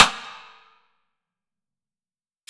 HARD PINCH
BA-BellySlap-Hard-Pinch.wav